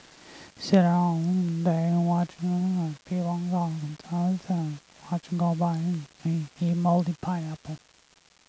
This parrot had learned to talk by listening to people walking at a distance. He spoke few words that you could actually understand. Instead, he mumbled.
Mumbling parrot.wav